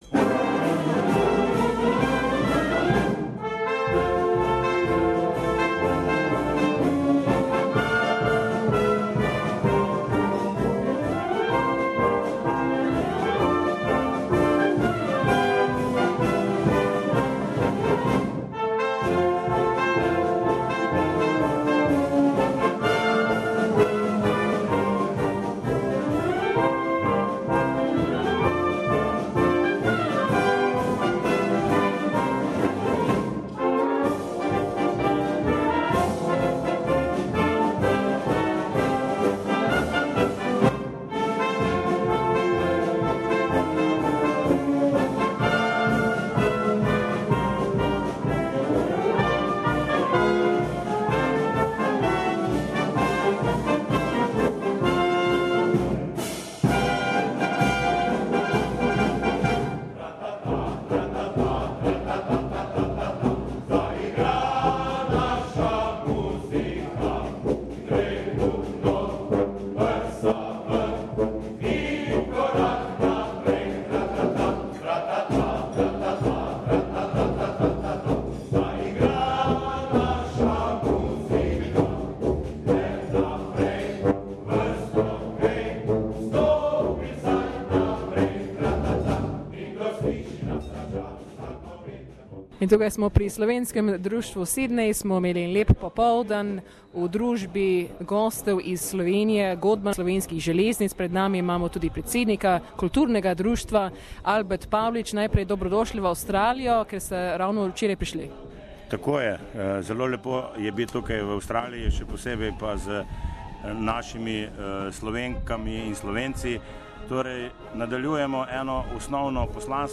V nedeljo 19. februarja je pri Slovenskem društvu Sydney Godba Slovenskih Železnic priredil odličen koncert za slovensko skupnost.
Slovenian Railways orchestra at Slovenian Association Sydney Source: SBS Slovenian